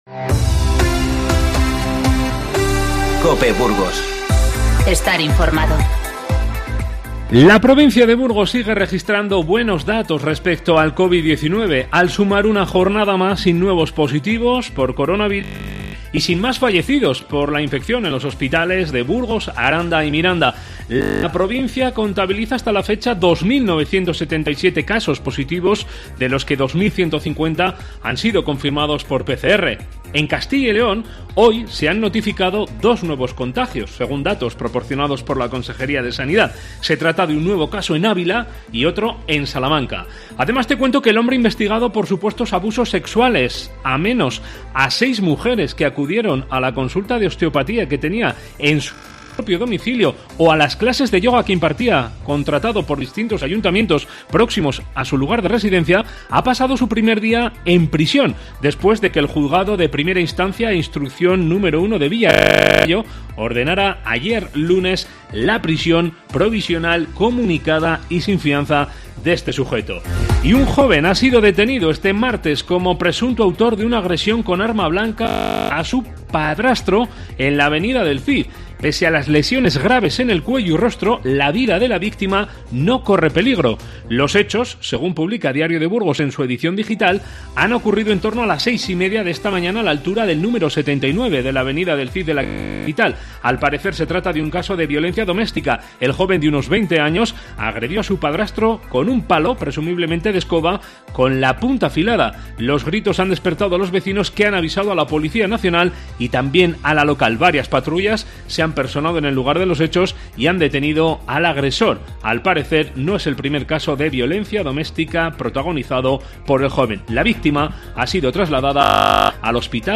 Informativo 30/6